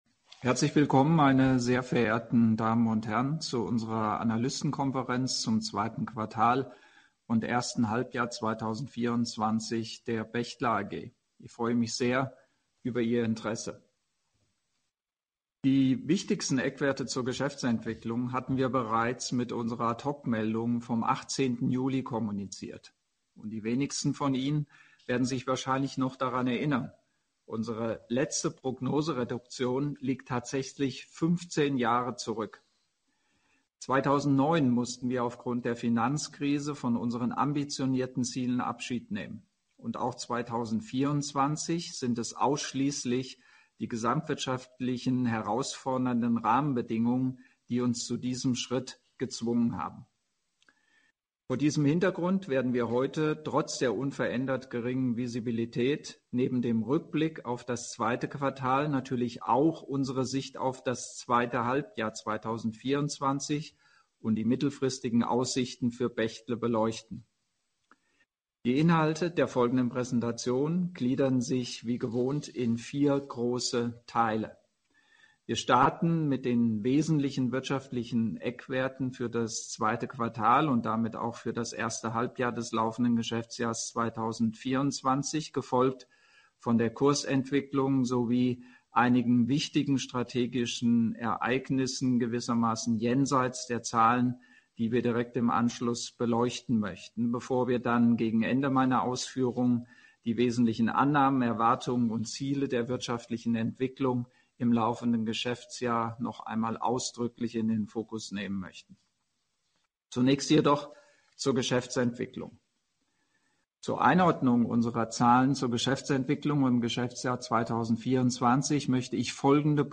mitschnitt_analystenkonferenz_de_q2_2024.mp3